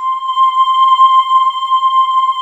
Index of /90_sSampleCDs/USB Soundscan vol.28 - Choir Acoustic & Synth [AKAI] 1CD/Partition D/19-IDVOX FLT